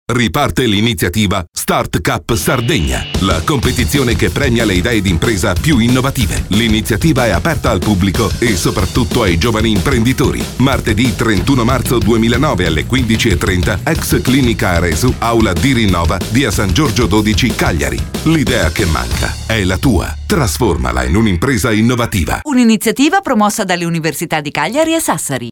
spot radiolina